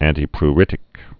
(ăntē-pr-rĭtĭk, ăntī-)